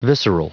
Prononciation du mot visceral en anglais (fichier audio)
Prononciation du mot : visceral